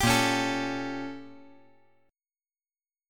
A#mM13 Chord
Listen to A#mM13 strummed